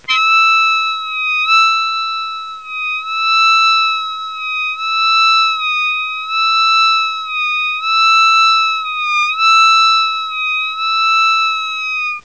On a C harp this note is E and Eb
Blow8 Bend8 Blow8 Bend8
blow8bend8.wav